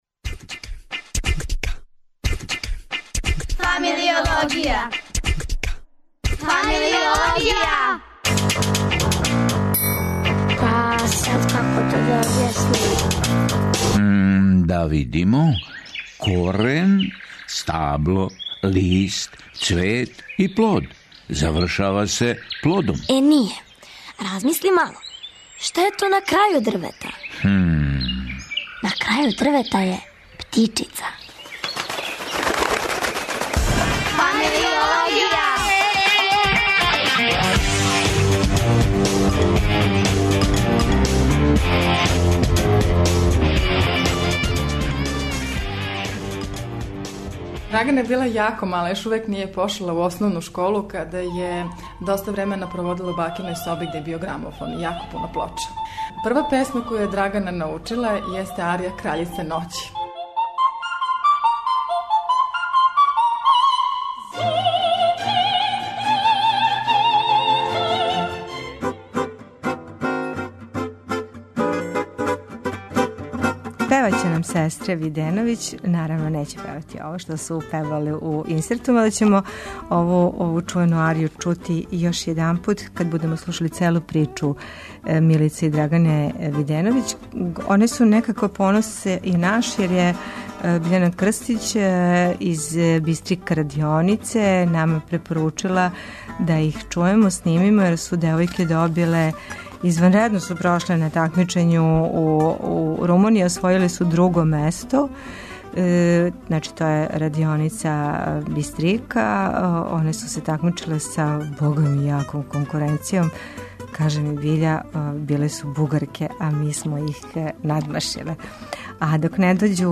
И певају (је)!